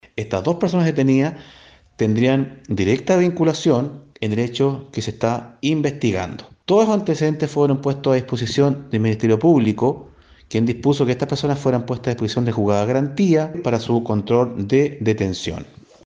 Sostuvo el oficial que los sujetos tendrían directa relación con el incendio de este automóvil que fue destruido en su totalidad, en un sector poblacional de la ciudad de Ancud.